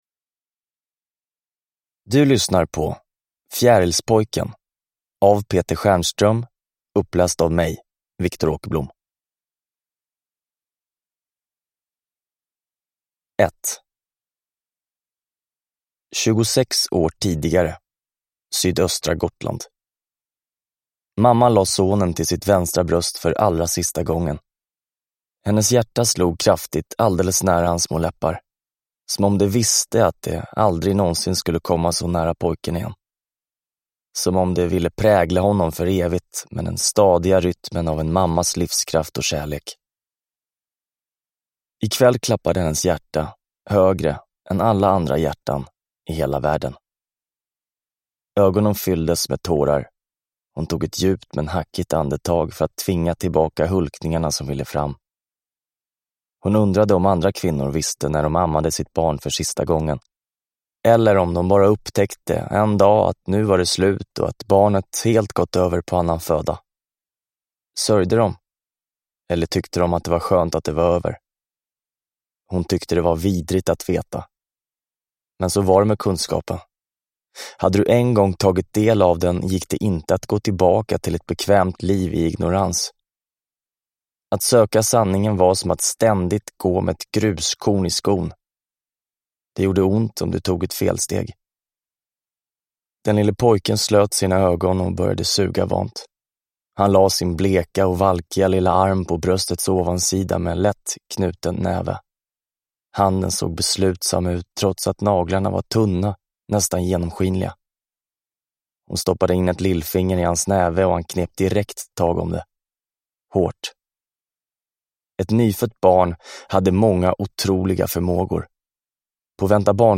Fjärilspojken – Ljudbok – Laddas ner